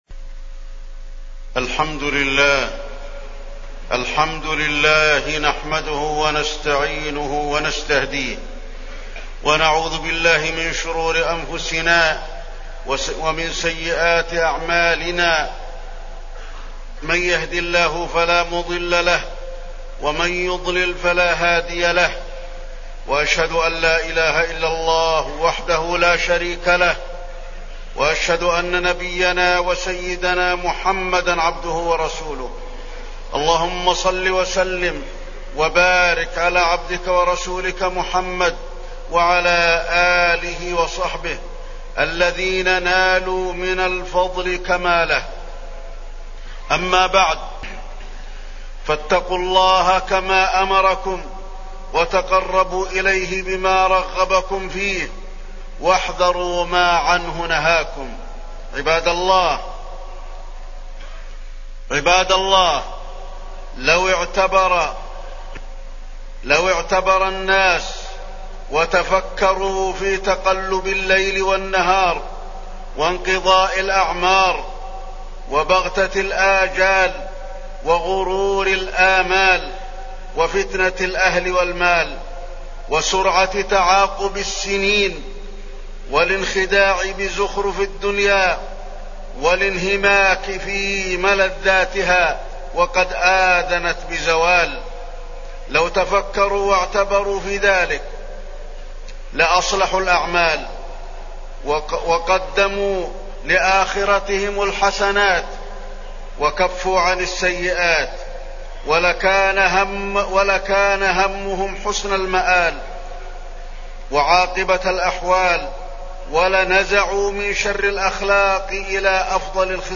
تاريخ النشر ١٤ ذو الحجة ١٤٢٩ هـ المكان: المسجد النبوي الشيخ: فضيلة الشيخ د. علي بن عبدالرحمن الحذيفي فضيلة الشيخ د. علي بن عبدالرحمن الحذيفي الاستعداد للآخرة في هذه الحياة الدنيا